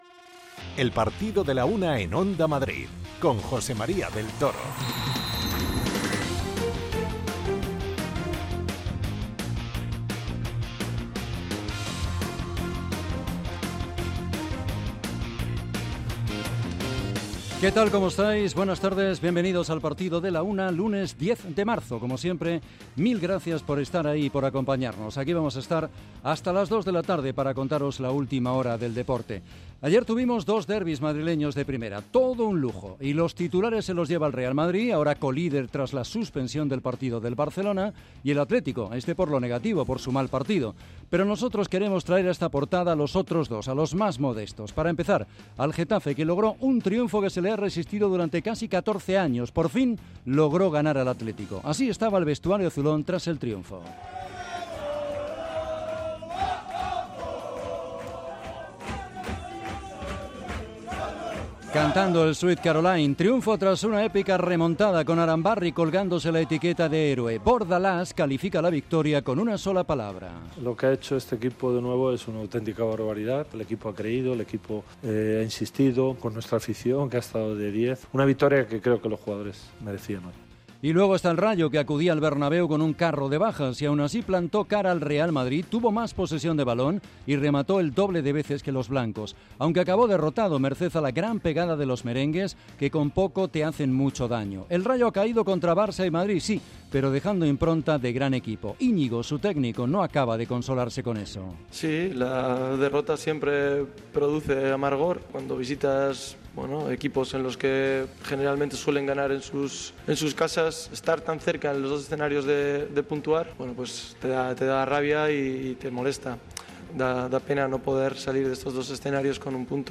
Analiamos el choque desde los dos puntos de vista: escuchamos a Ancelotti, Valverde, Iñigo Pérez, Pedro Díaz y Gumbau. 2-1.